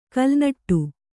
♪ kalnaṭṭu